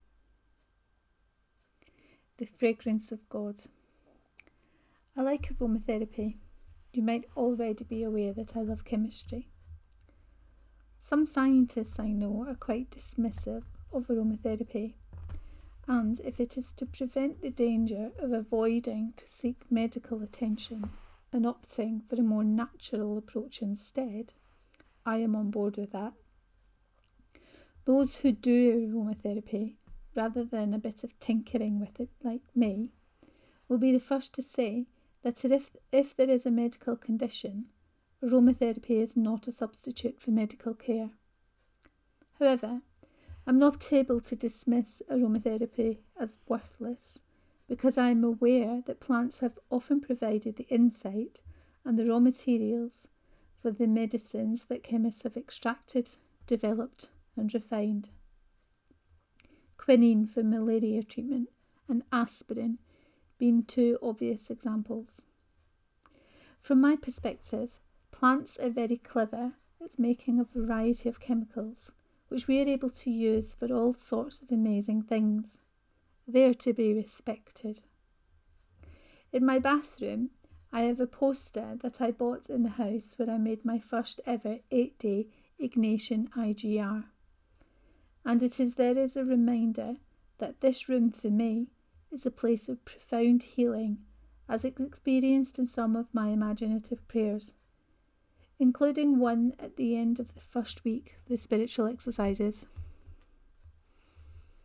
The Fragrance of God 1: Reading of this post.